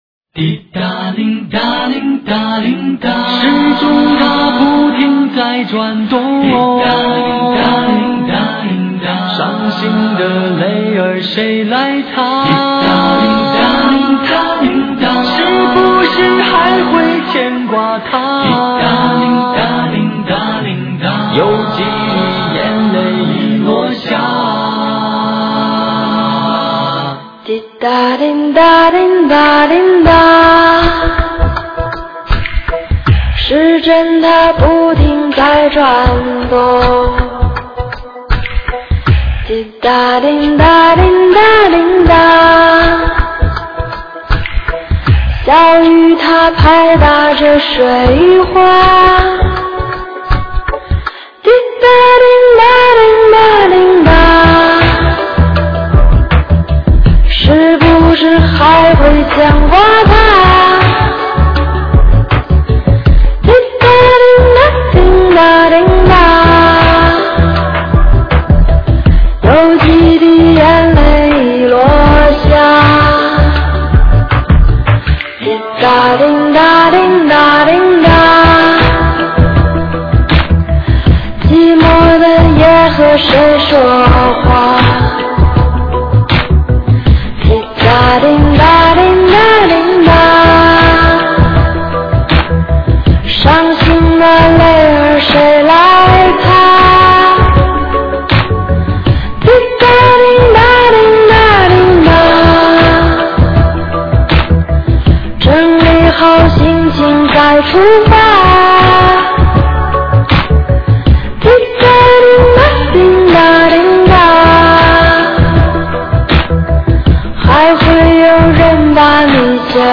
这天籁一样的纯净的声音，在耳边轻轻环绕，怎么形容这个声音呢？
这个略带沙哑的声音，像夏日汗流浃背中咬开的第一口井水浸过西瓜瓤，是的，沙甜，清凉，沁人心脾。